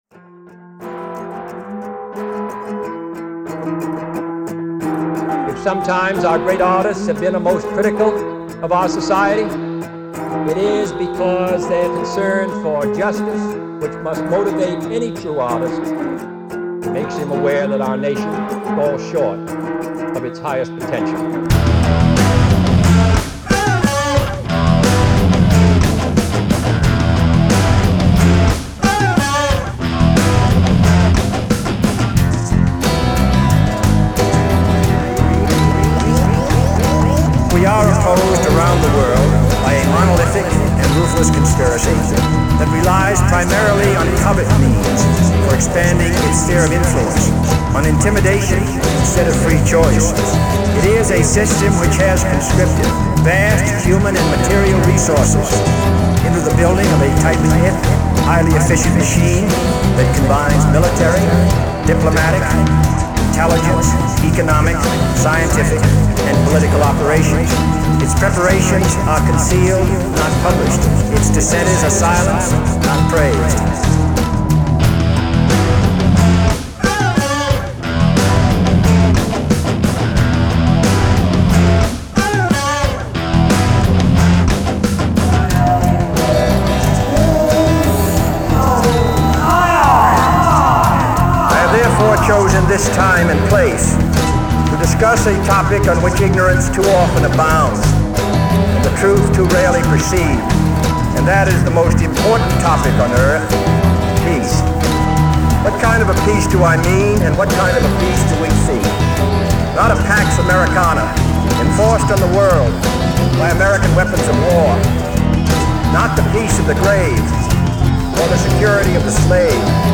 The genre is Rock, I suppose, but I highly encourage listening to the song to get a feel for it.